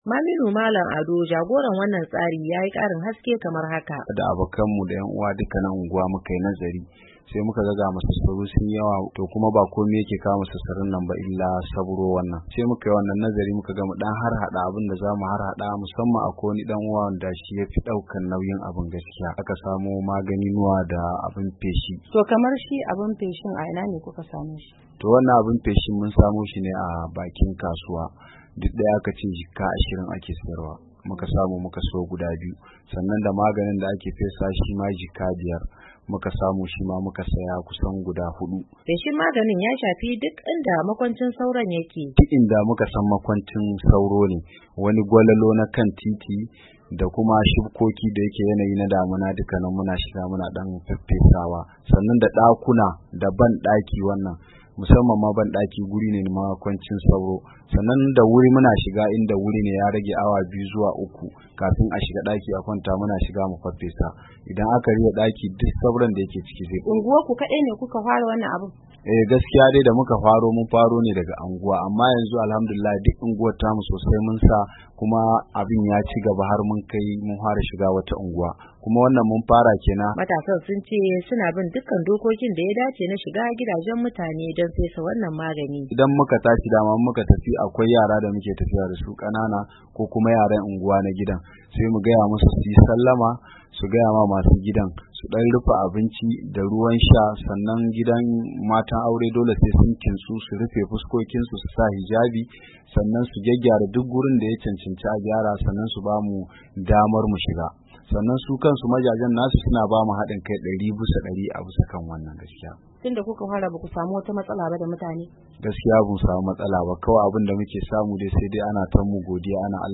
Wasu da suka ci moriyar wannan tsari sun ce ba karamin dadawa 'yan unguwar ya yi ba, inda mazauna unguwannin suka ce yanzu haka sauro ya yi sauki sosai.